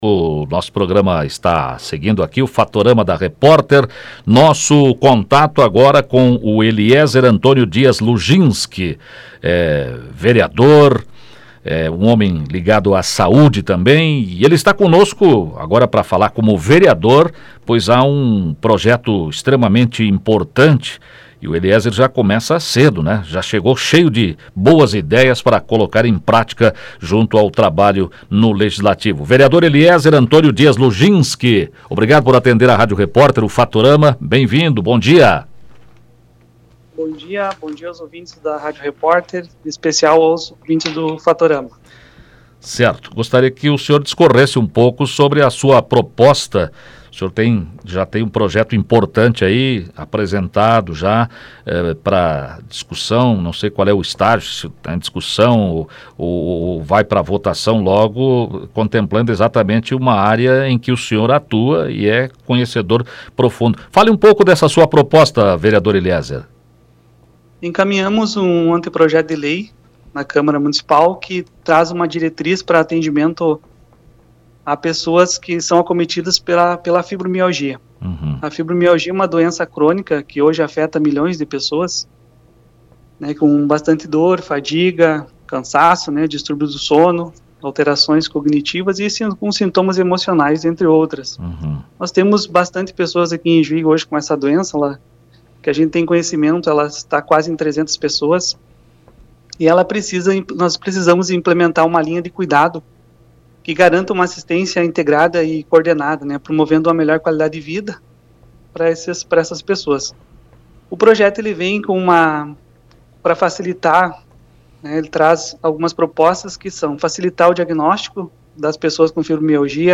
O vereador Eliezer Luginski, conhecido como Eliezer da Saúde (PP), participou do programa Fatorama desta segunda-feira, dia 21 de janeiro e com compartilhou como têm sido os primeiros dias na função de vereador..